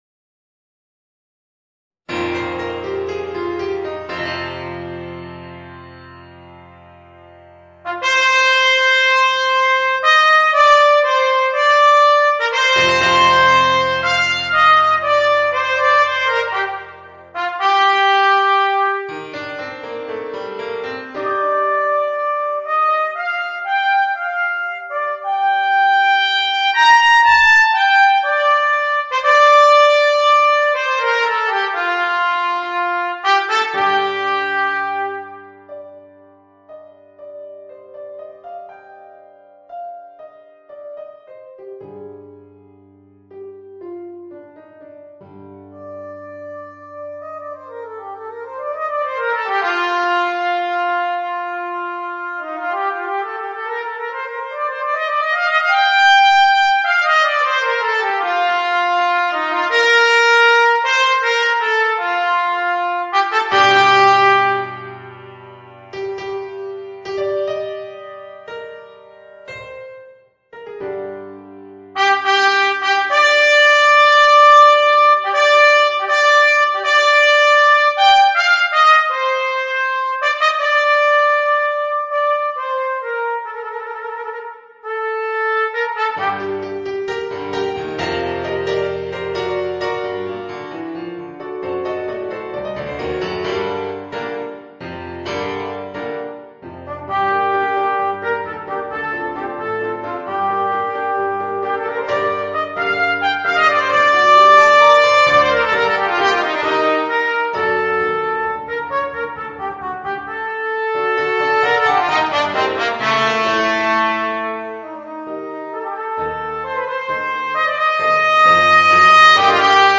Voicing: Trumpet